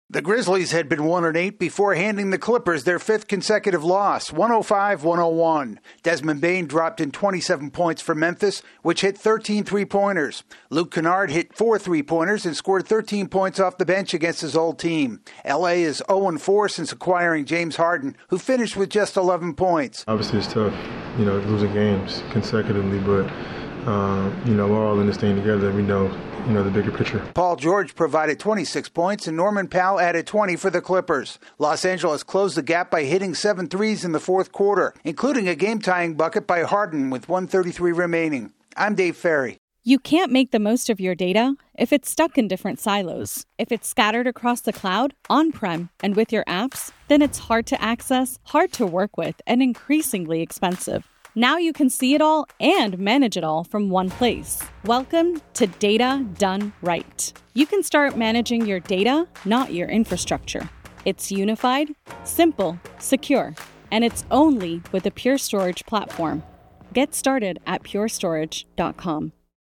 The Clippers continue to stumble since aquiring an All-Star guard. AP correspondent